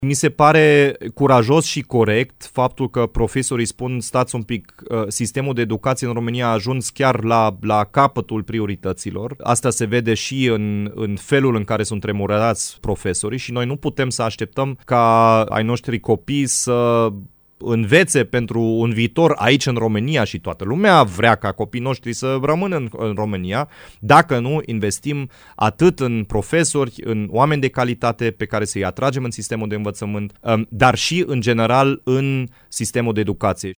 Prezent la Radio Timișoara, Dominic Fritz a spus că semnalul de alarmă tras de cadrele didactice asupra sistemului educațional este unul necesar.